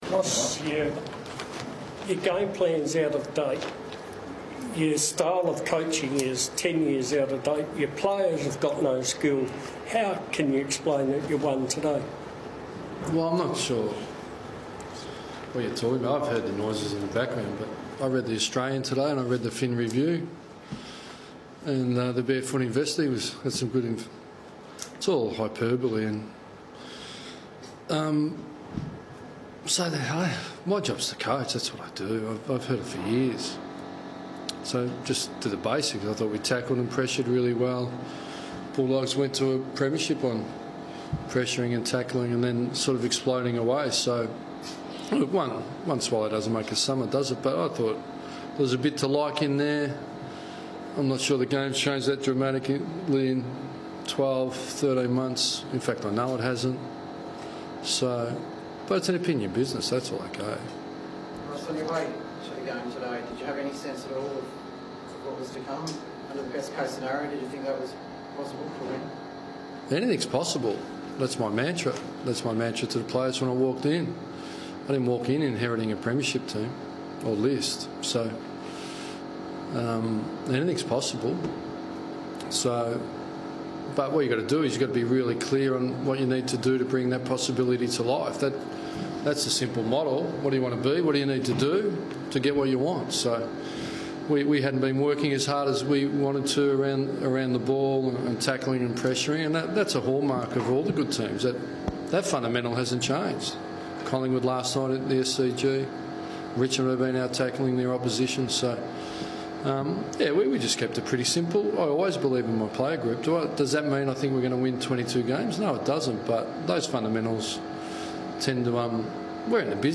Ross Lyon speaks to the media after Freo's win over the Dogs.